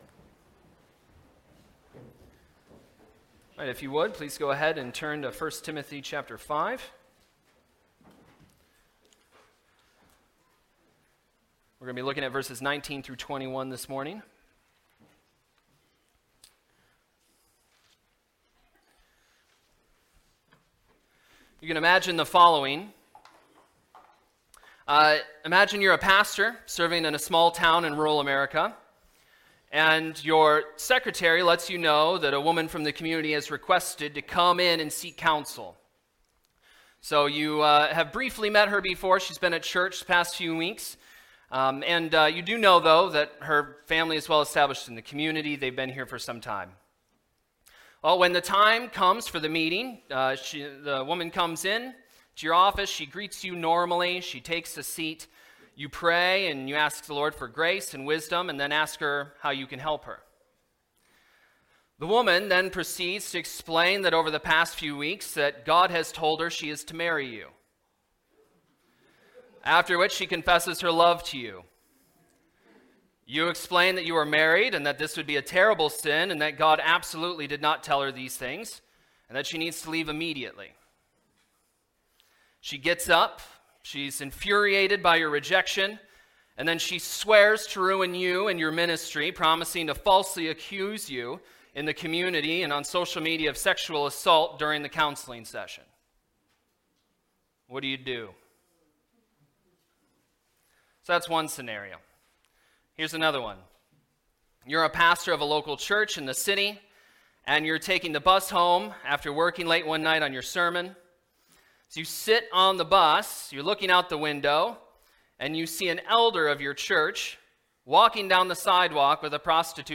Timothy Sermons